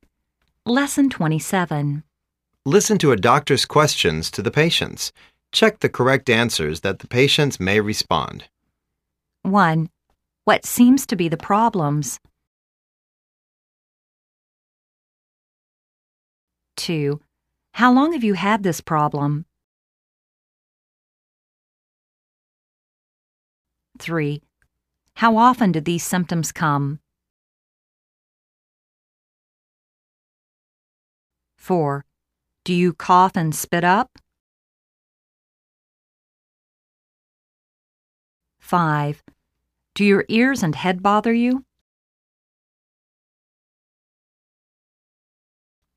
Listen to a doctor's questions to the patients.Check the correct answers that the patients may responsed.